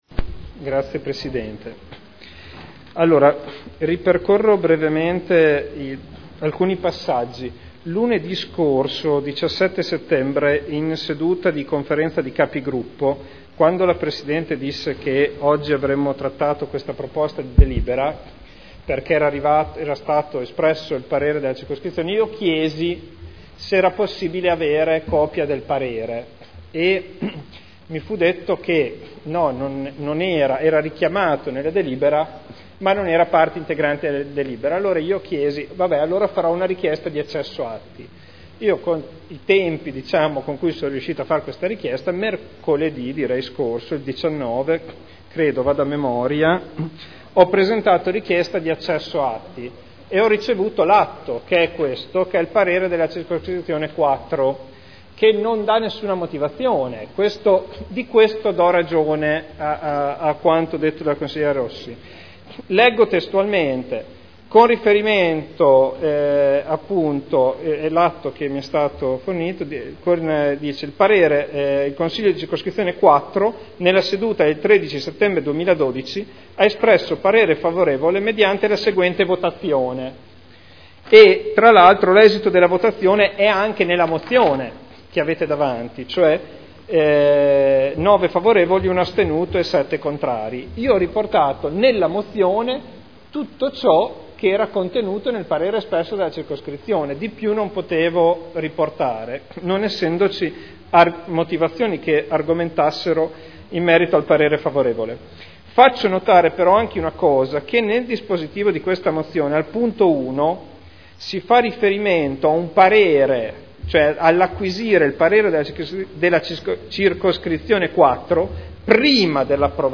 Seduta del 24/09/2012. Interviene contro a pregiudiziale su mozione prot. 111618